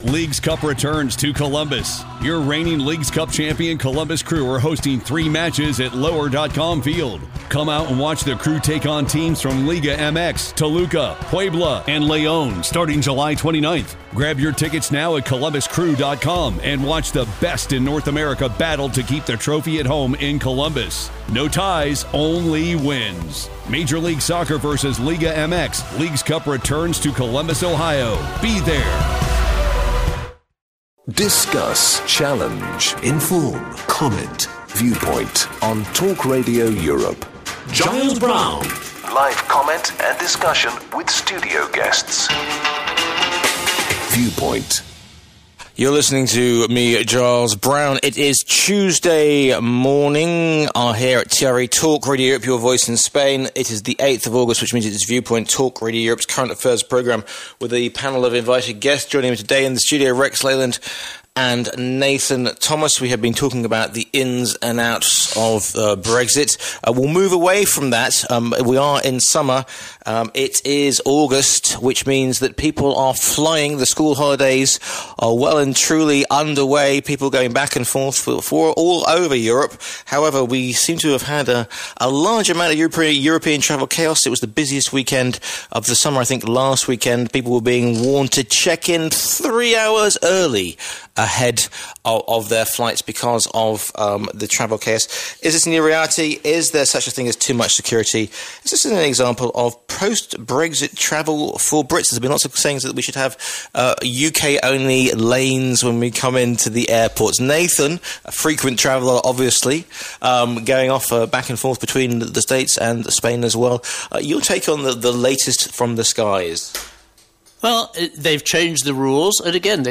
The panel discuss the major and breaking news stories of the week. The show is uncensored, often controversial, and full of heated debate.